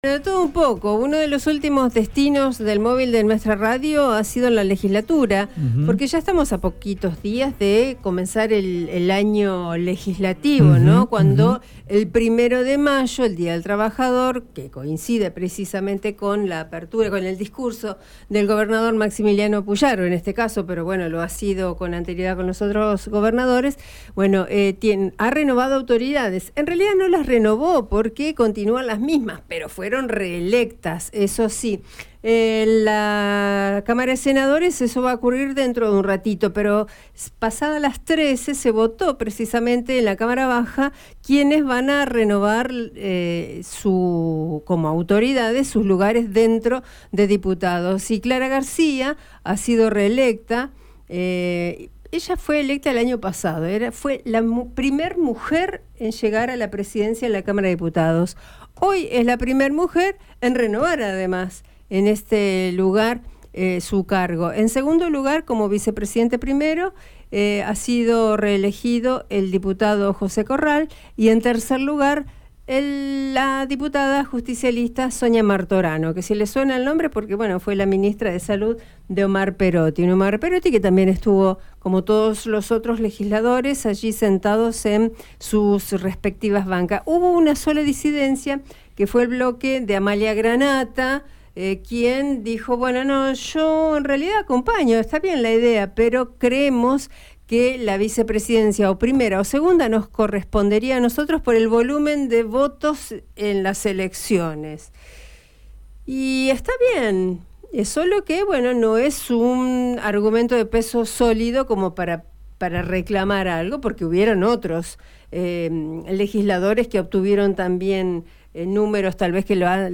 En diálogo con el móvil de RADIO EME, la diputada socialista Clara García destacó la importancia que tiene que las mujeres tomen lugares de poder al tiempo que adelantó las posibles discusiones que podrían llevarse adelante durante el año.